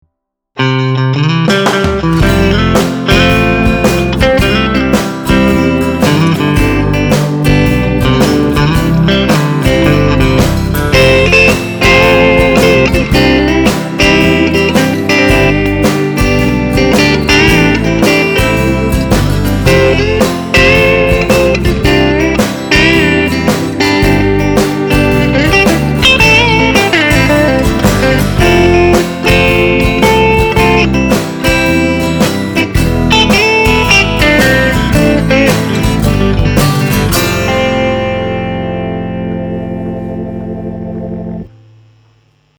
Gitarové Kombo Trubica Zosilnovača
Metropolitan20Country.mp3